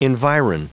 Prononciation du mot environ en anglais (fichier audio)
Prononciation du mot : environ